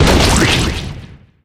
squeak_bomb_01.ogg